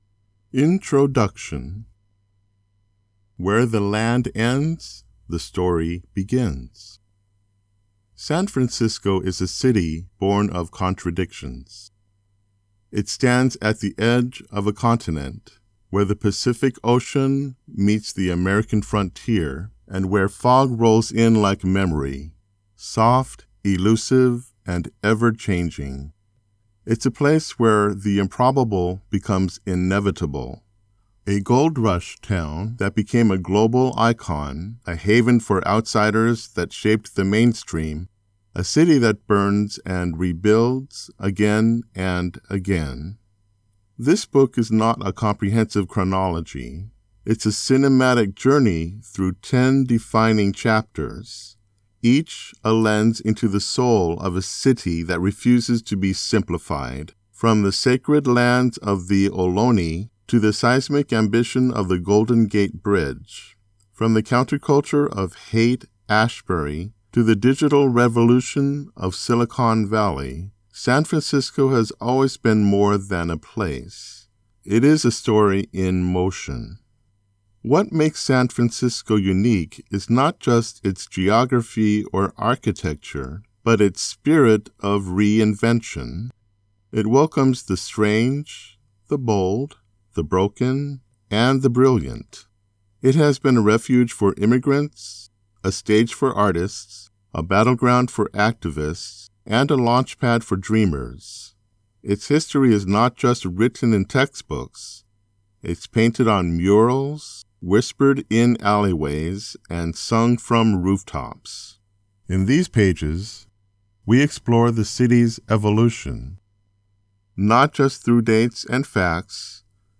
Standard American Male accent
Audiobook